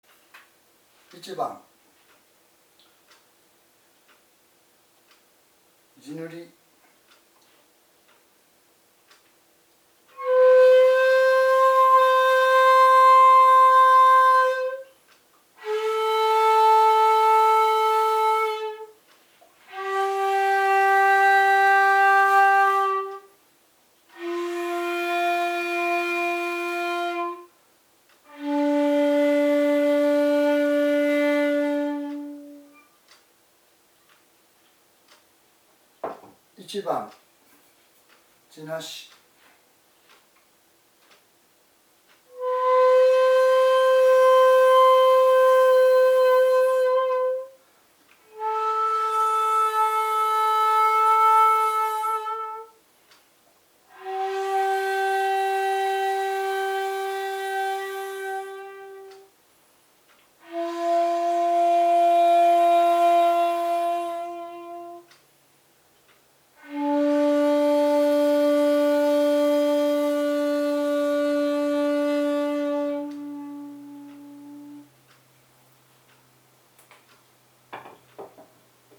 今回は如何に地無し管で琴古流本曲が生き返ってくるか、を地塗り管と実際に吹き比べてみたいと思います。
１．地無し管は各孔の音色が個性的である。